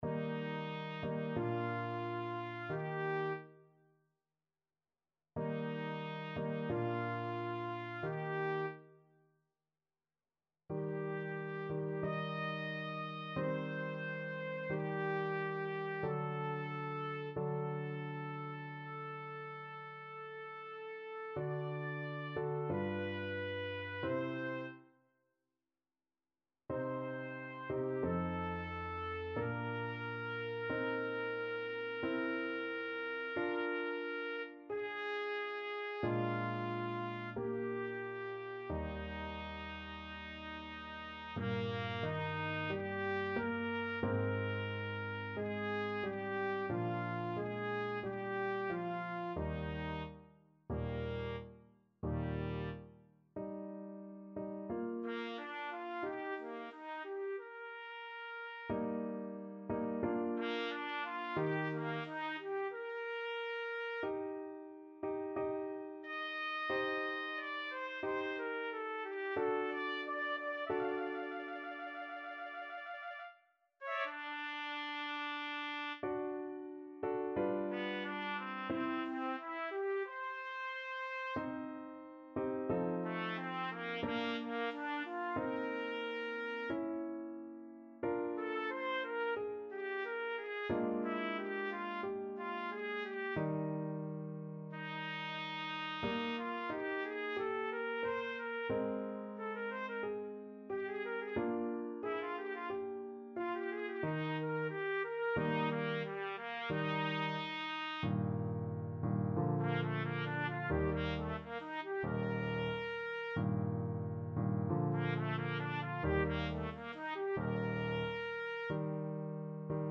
Larghetto =c.45
4/4 (View more 4/4 Music)
Classical (View more Classical Trumpet Music)